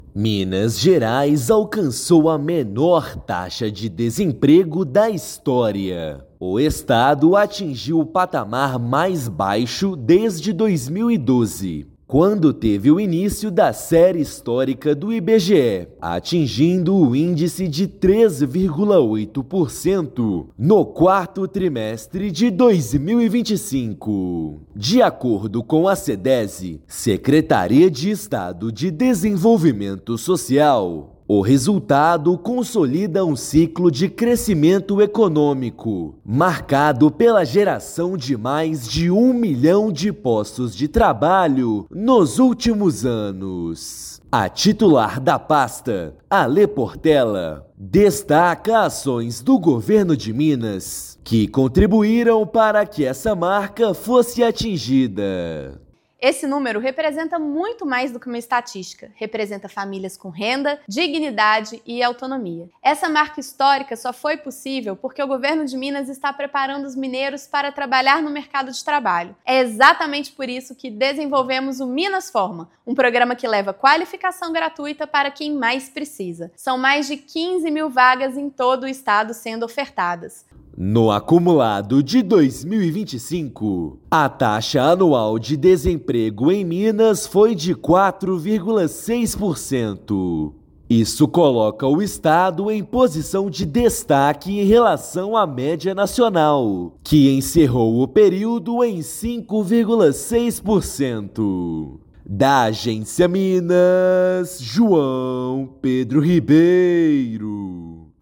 Taxa de 3,8% no quarto trimestre de 2025 foi impulsionada por atração de investimento e geração recorde de empregos. Ouça matéria de rádio.